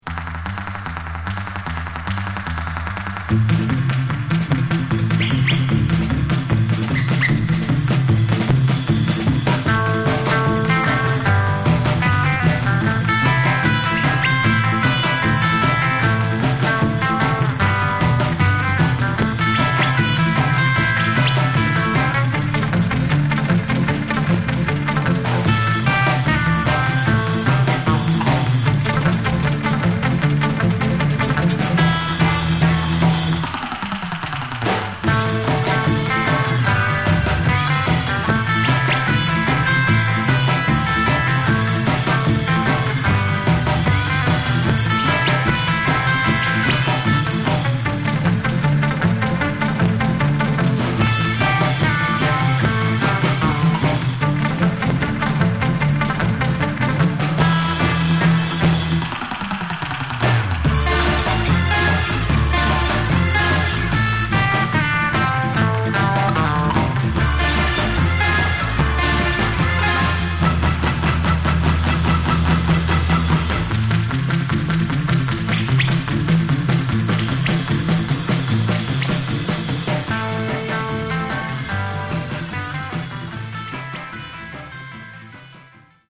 surf instrumental
Mono